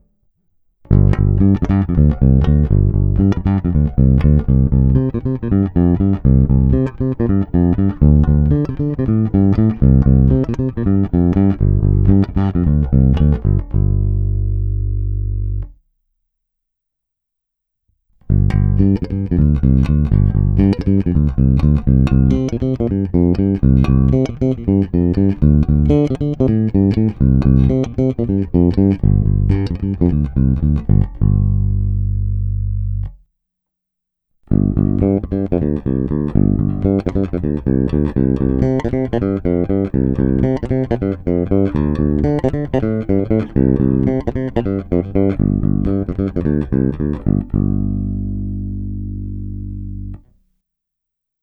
Není-li uvedeno jinak, následující nahrávky jsou provedeny rovnou do zvukové karty, v pasívním režimu a s plně otevřenou tónovou clonou.